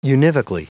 Prononciation du mot univocally en anglais (fichier audio)
Prononciation du mot : univocally